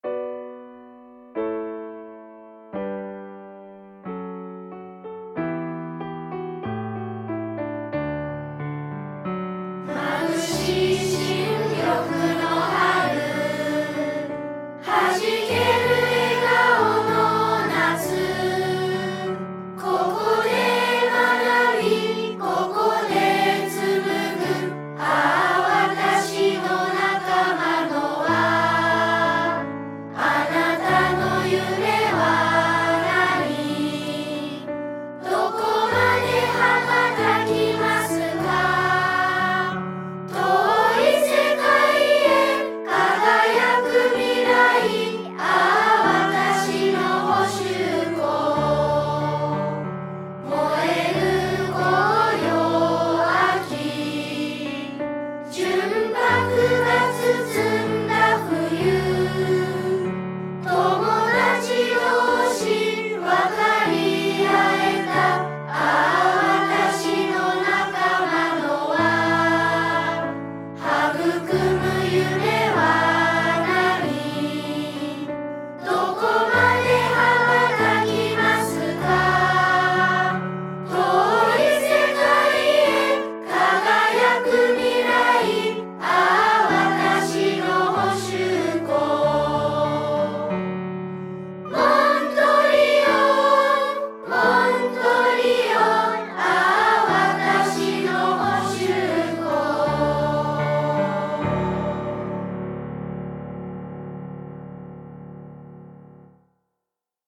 本校のスローガンを体現している子供達のそんな姿を、短く分かりやすい言葉で表現し、優しく覚えやすいメロディーに乗せられたら、という思いでできあがった校歌です。
● 歌いやすくていいんじゃない！
● 不思議と懐かしさを感じる素敵な校歌だと思います！